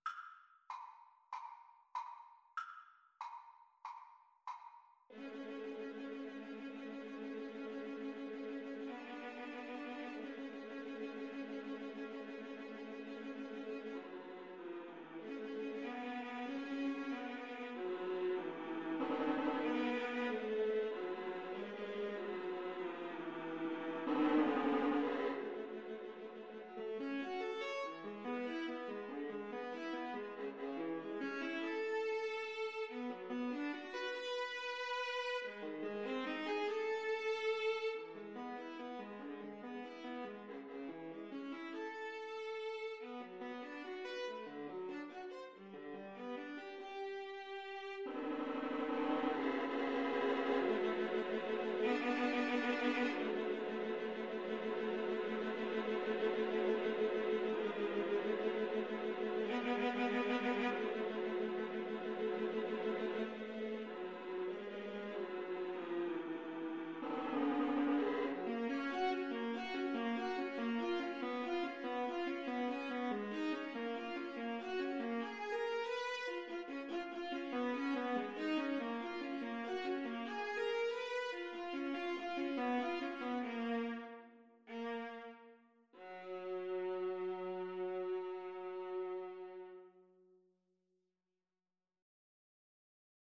F# minor (Sounding Pitch) (View more F# minor Music for Viola Duet )
Andante = 95
Classical (View more Classical Viola Duet Music)